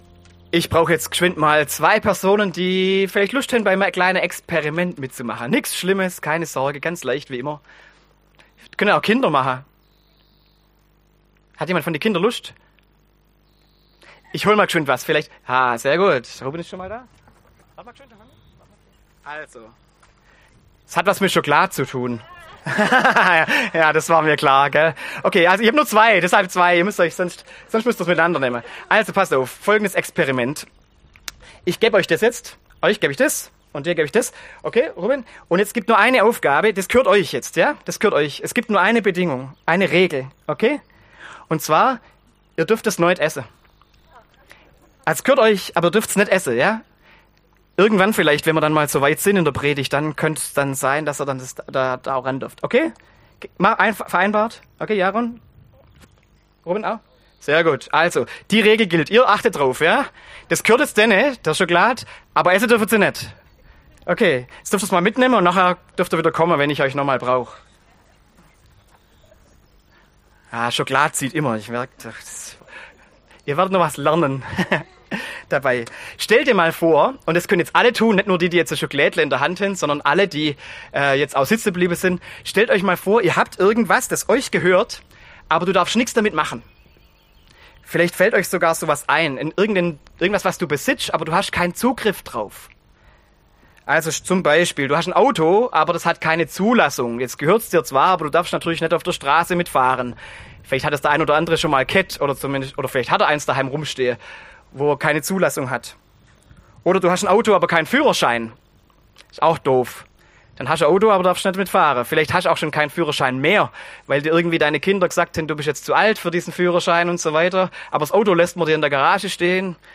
Predigt zum Dreieinigkeitsfest